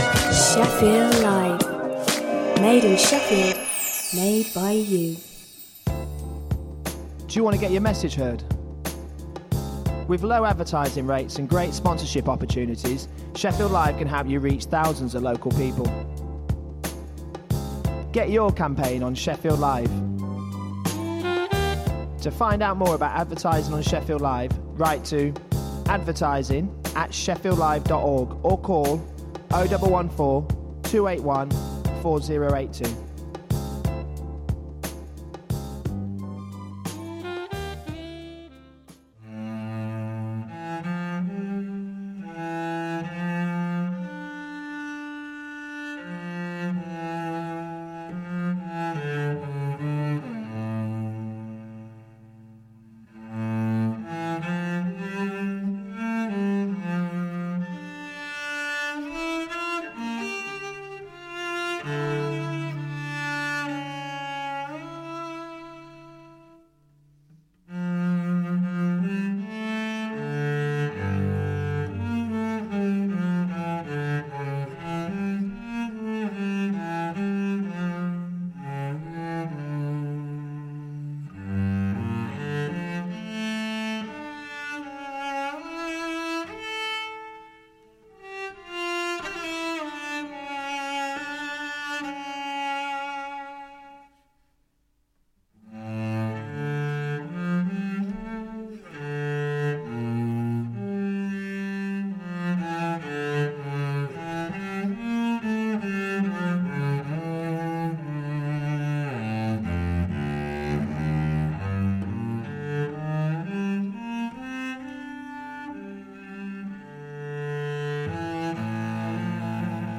Business news, debate and interviews for anyone interested in growing or starting a business.